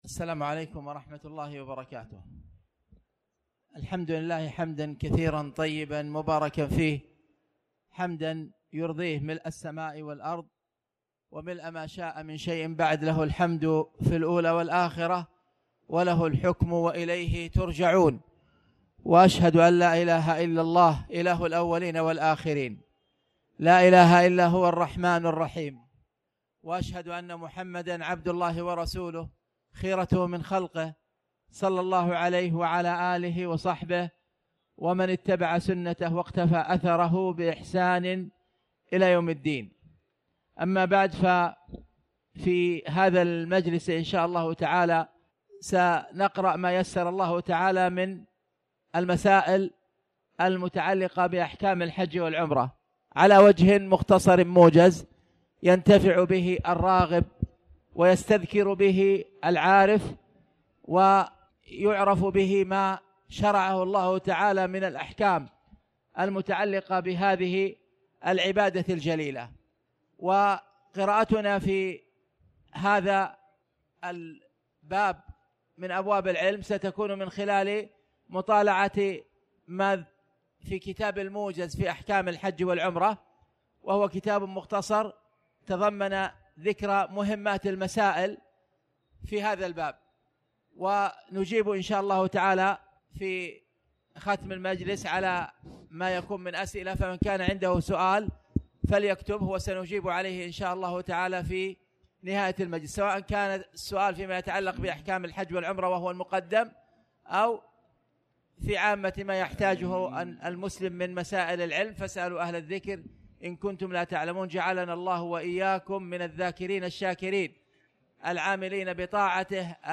تاريخ النشر ١١ ذو القعدة ١٤٣٩ هـ المكان: المسجد الحرام الشيخ: خالد بن عبدالله المصلح خالد بن عبدالله المصلح من أحكام الحج